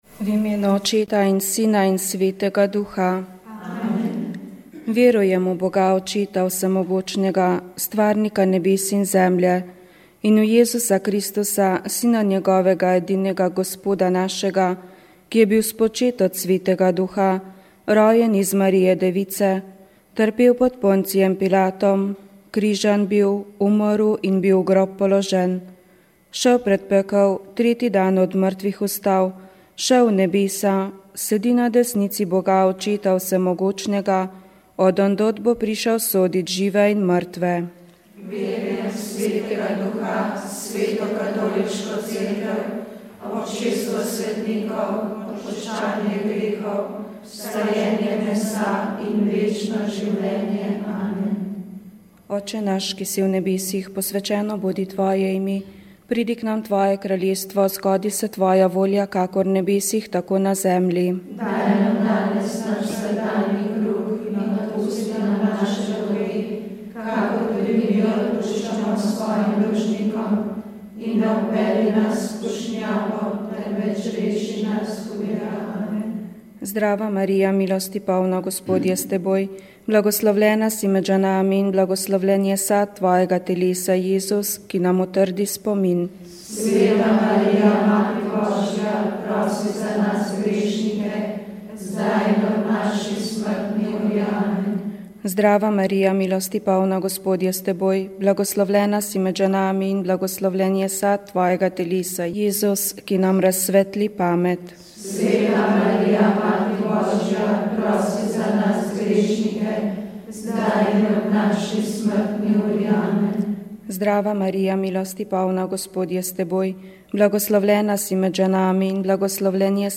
V novem delu radijskega romana Dragulj v pesku ste spet lahko poslušali odlomek iz knjige Tesse Afshar,, ki je izšla pri založbi Družina.